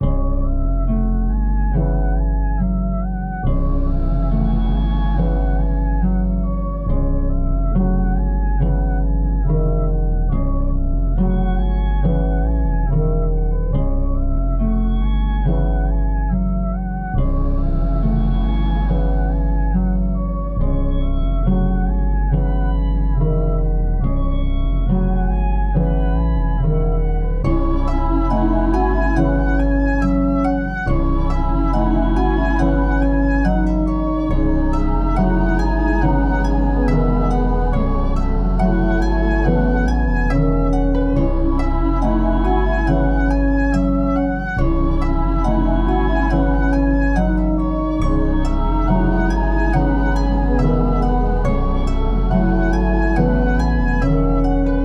Perfect for Trap, but works well with R&B and Pop too. Dark and deep textures to bring more fill to your songs.